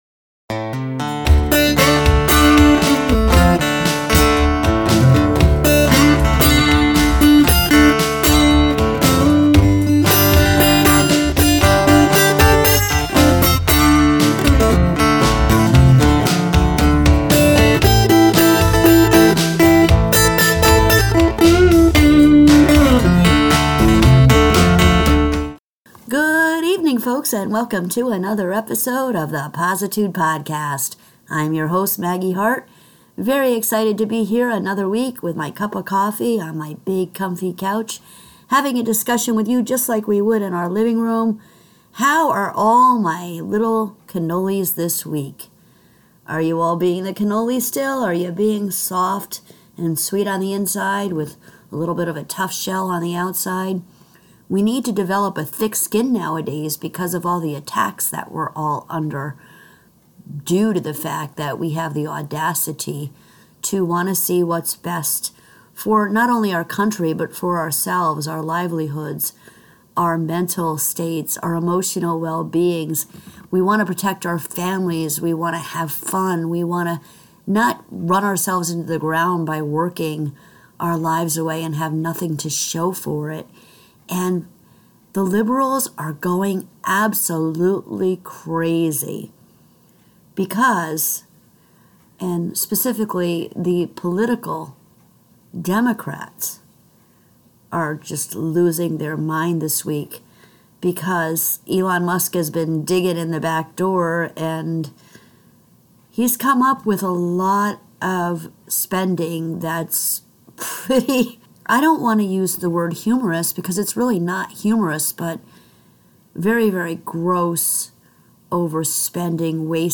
Each episode features in-depth discussions on a wide range of subjects, including: Social and political issues Philosophy and spirituality Science and technology Personal growth and development